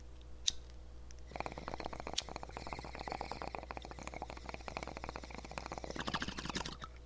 水管
描述：我用一个小水烟筒（我只是弹了一下打火机，我没有抽烟）
Tag: 气泡 气泡 效果 更轻 声音 波浪